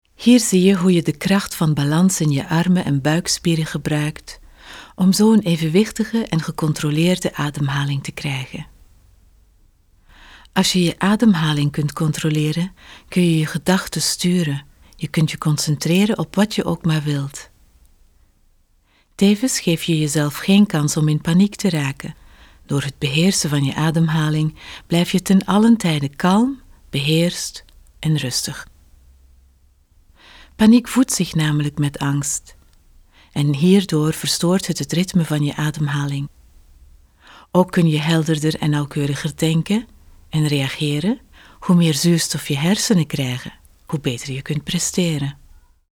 Sprechprobe: Sonstiges (Muttersprache):
TV-Interpreter Professional voice talent Flemish and European English